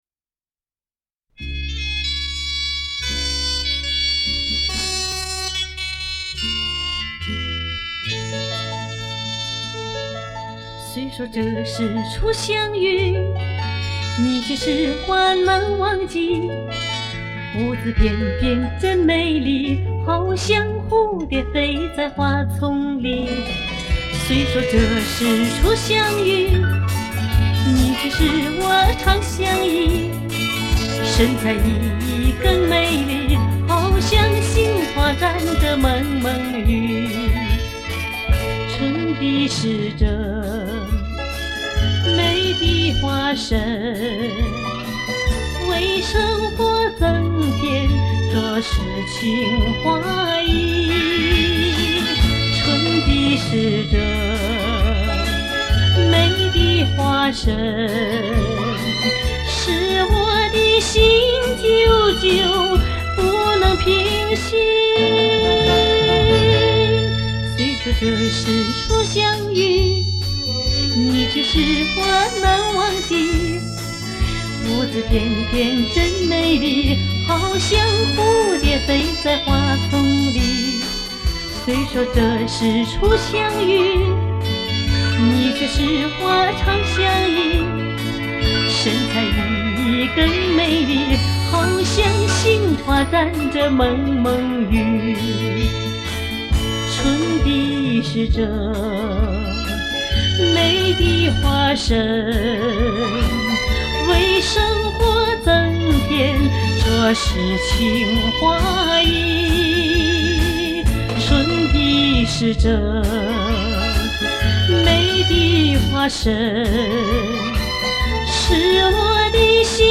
经典的老磁带